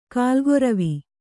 ♪ kālgoravi